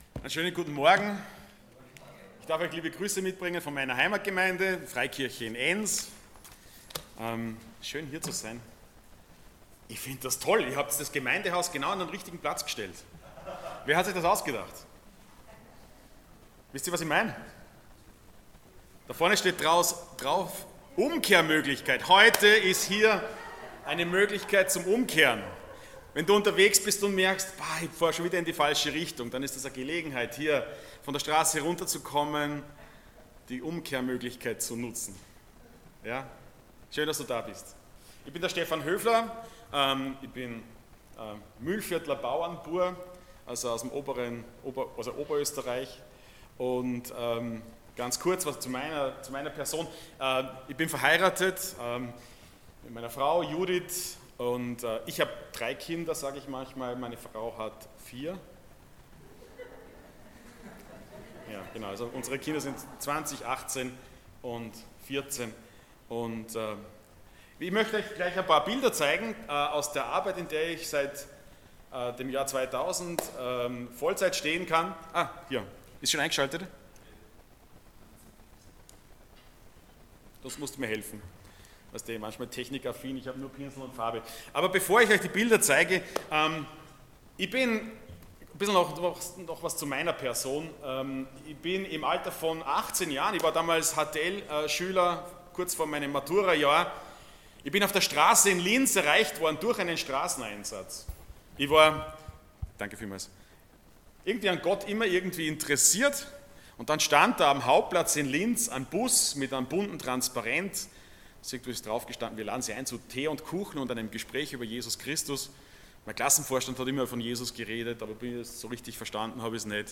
Passage: Romans 3:1-20 Dienstart: Sonntag Morgen